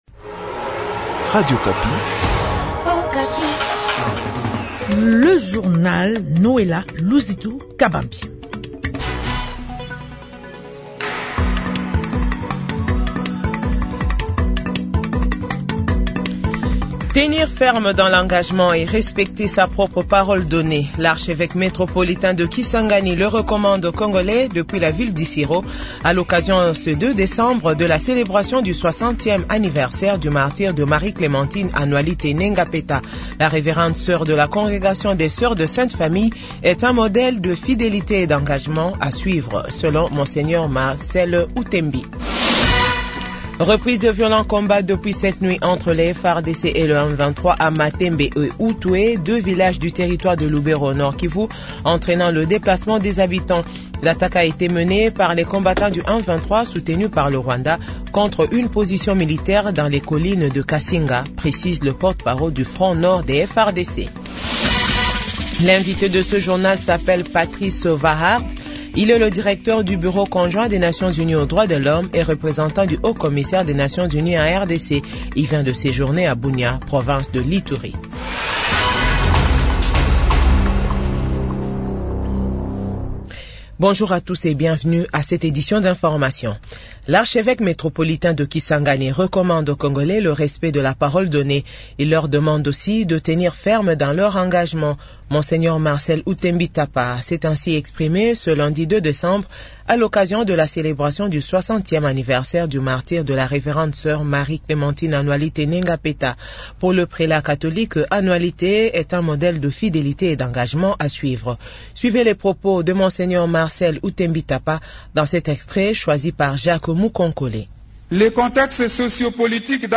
JOURNAL FRANÇAIS DE 18H00